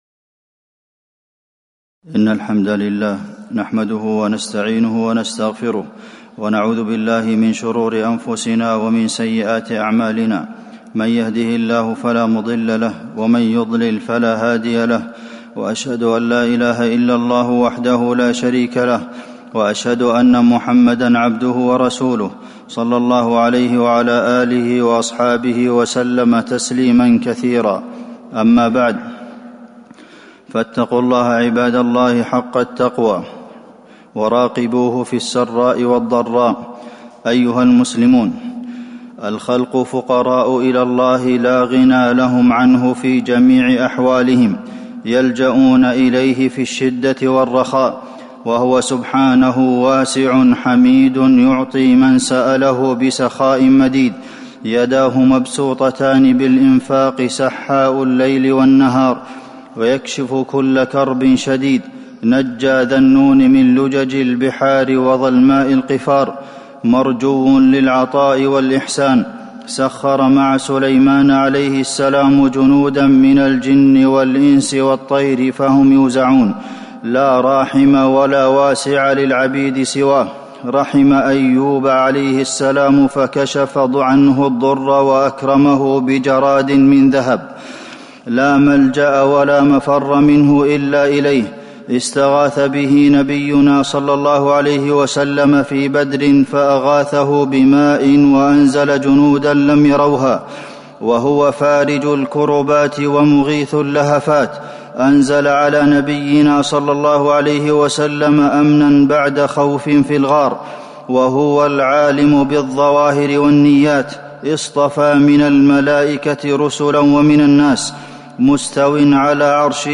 خطبة الاستسقاء - المدينة - الشيخ عبدالمحسن القاسم
المكان: المسجد النبوي